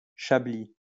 Chablis (French pronunciation: [ʃabli]